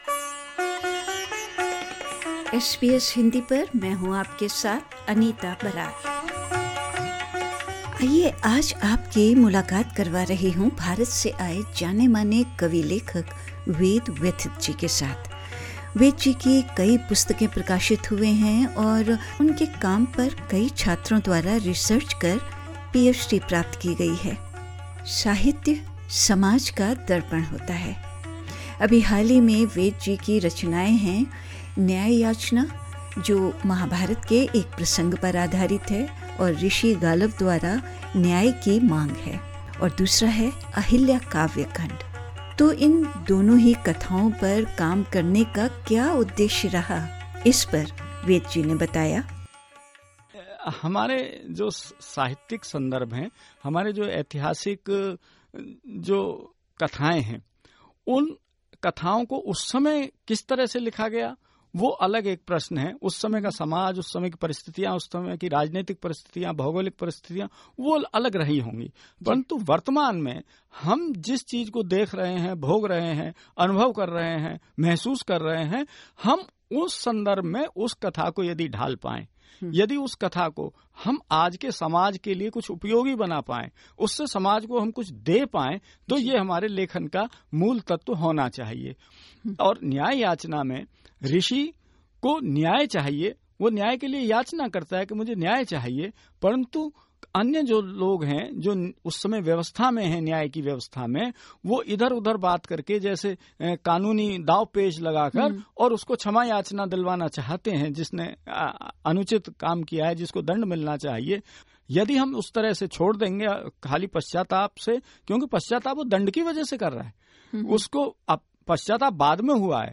इन्टरव्यु